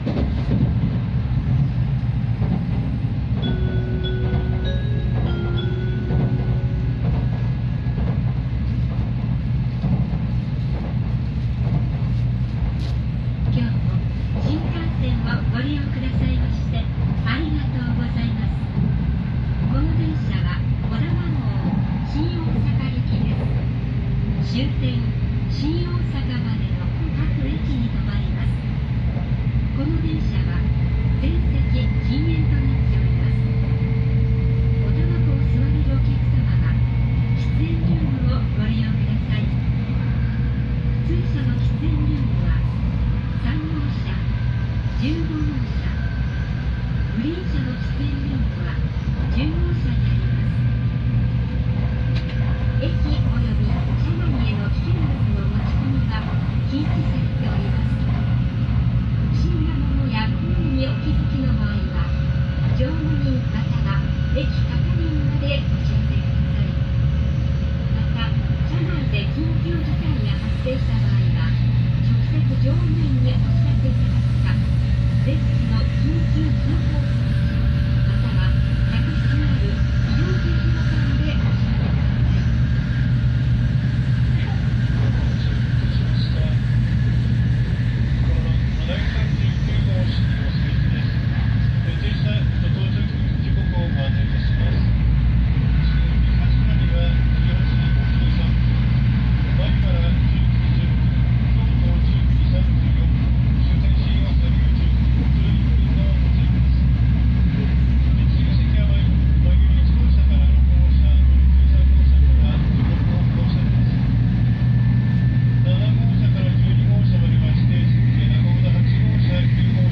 [走行音] ‘220504 こだま739号 名古屋→岐阜羽島 出発後アナウンス
（名古屋駅出発直後、枇杷島付近から）